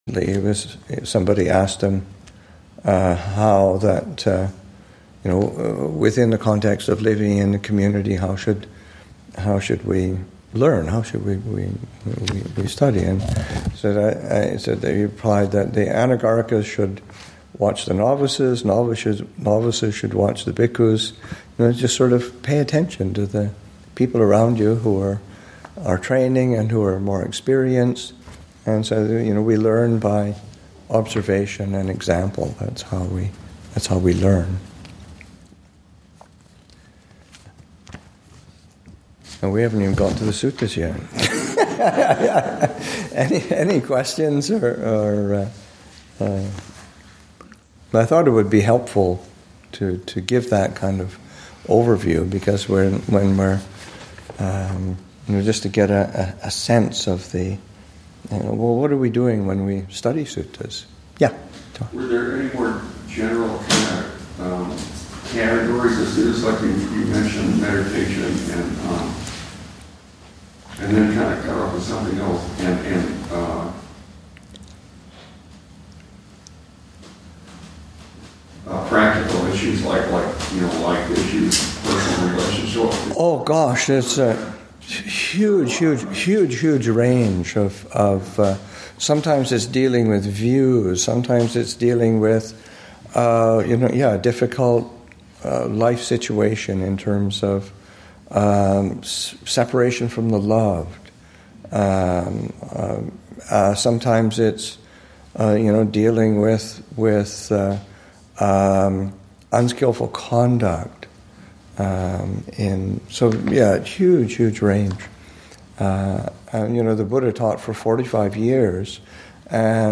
Abhayagiri Buddhist Monastery in Redwood Valley, California and online